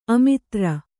♪ amitra